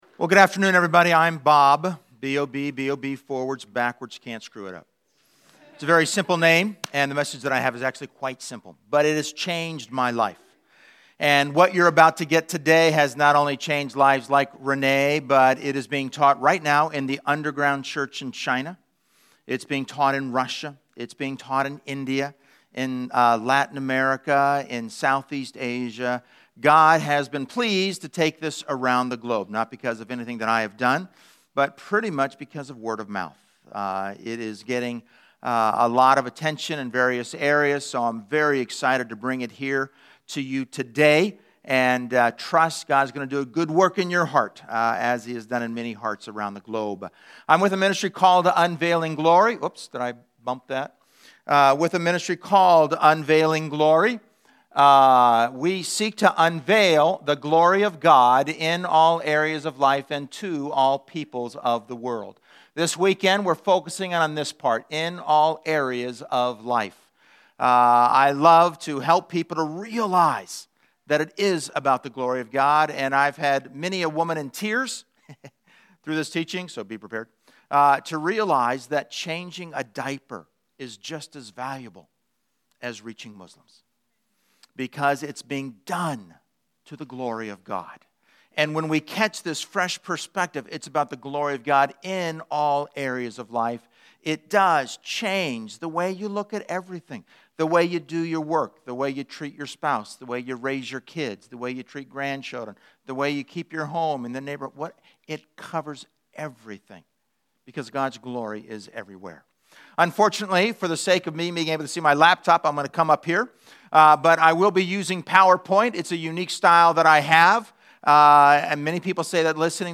Check out these audio recordings and PDF notes from the Cat & Dog Theology Conference held at FBC.